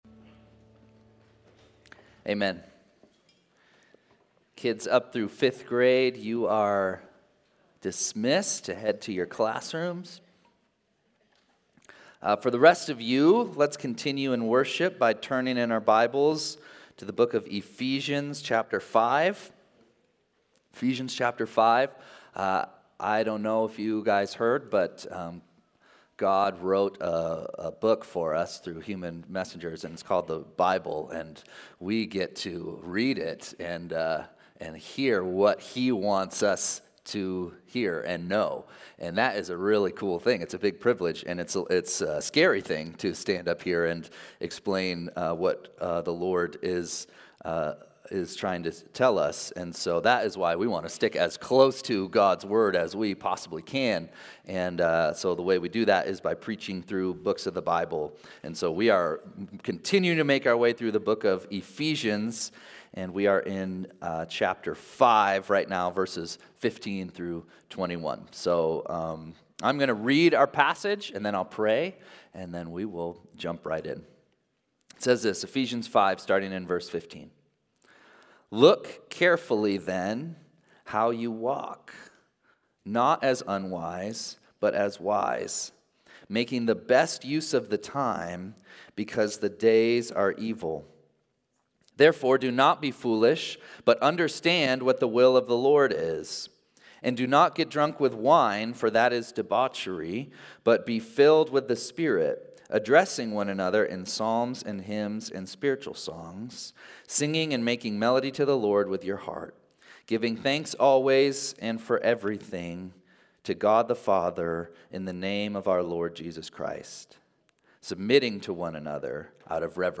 Sunday-Service-7-7-24.mp3